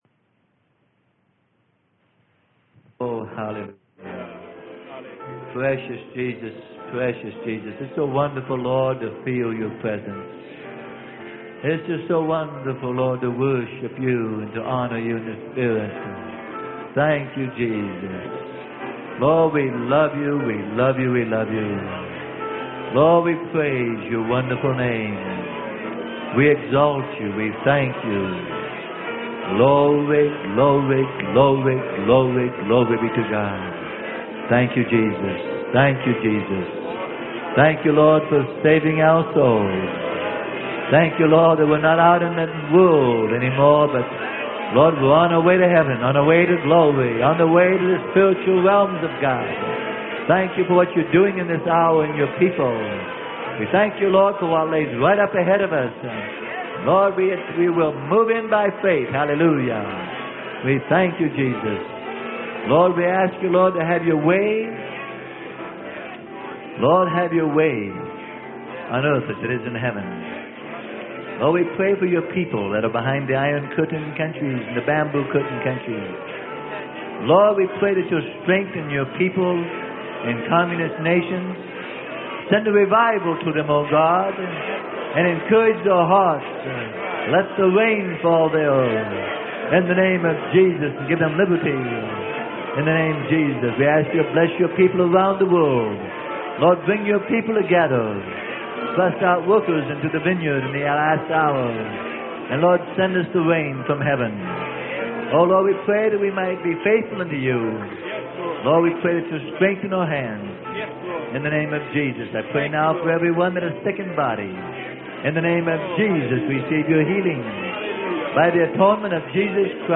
Sermon: God's Church - Part 2 - Freely Given Online Library